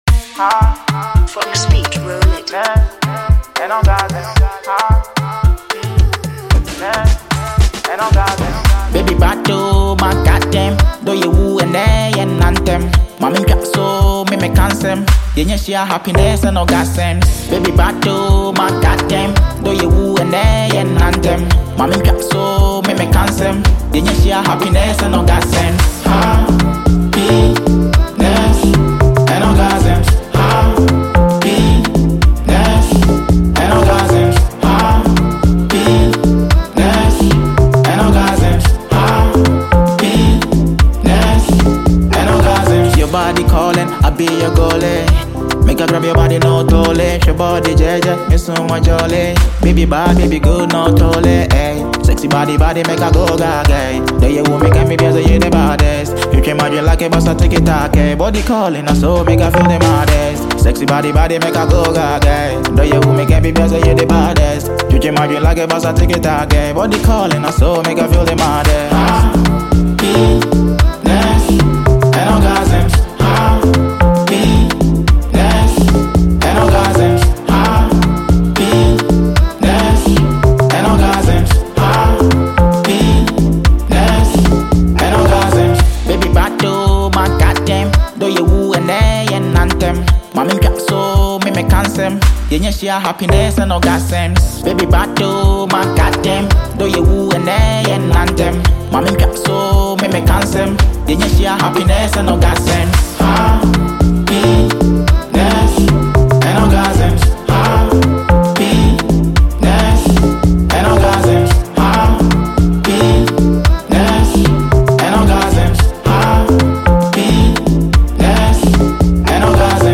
The song features catchy beats and thoughtful lyrics